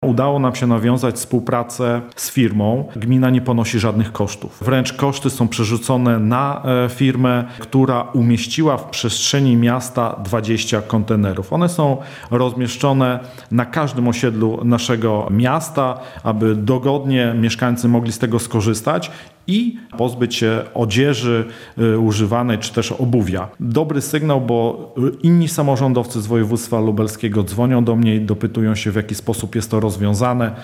Udało nam się znaleźć rozwiązanie – mówi burmistrz Łęcznej Leszek Włodarski.